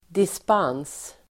Uttal: [disp'an:s (el. -'ang:s)]